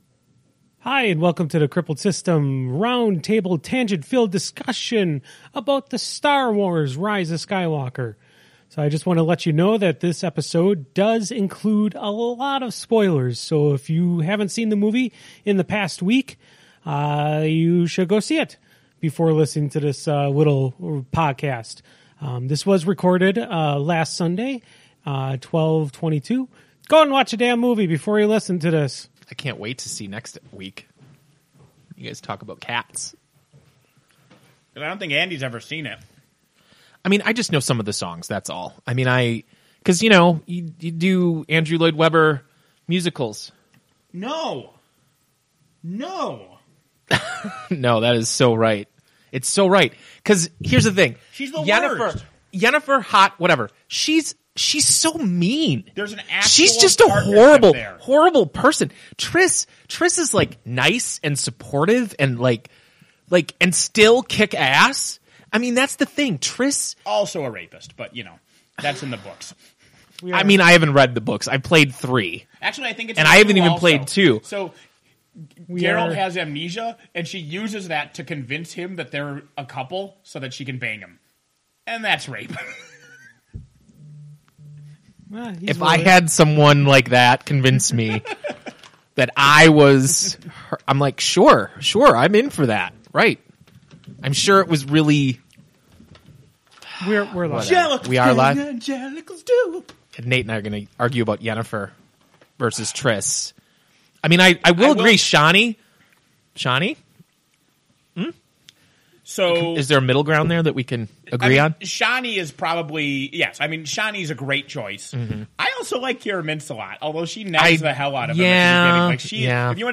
we have a tangent filled Star wars round table discussion.